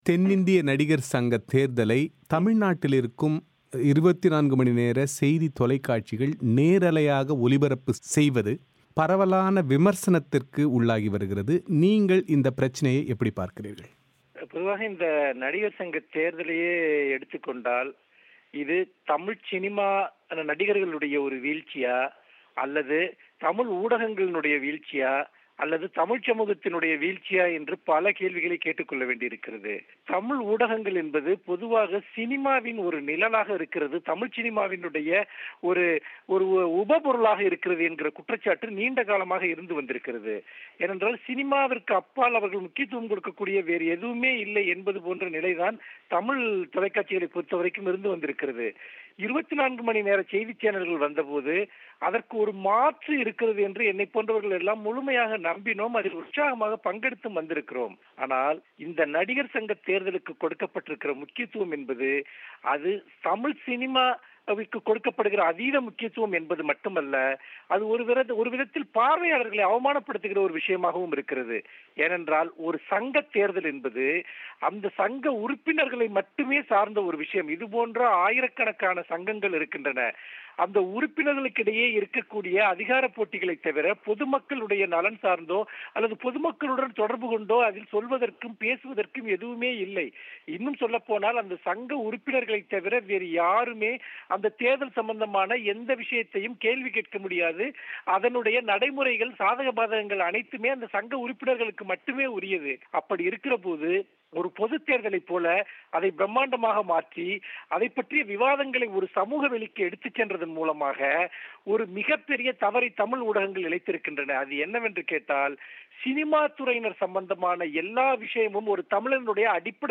இது குறித்து மனுஷ்யபுத்திரன் பிபிசி தமிழோசைக்கு அளித்த விரிவான செவ்வியின் ஒலி வடிவத்தை நேயர்கள் இங்கே கேட்கலாம்.